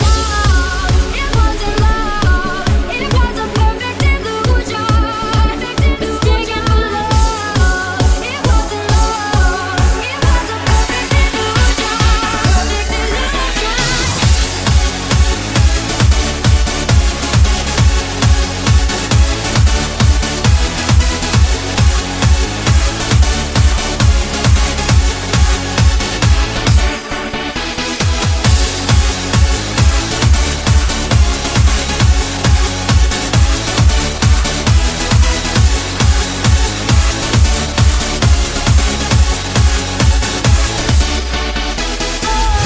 hits remixed
Genere: cover, edm, club. techno, successi, remix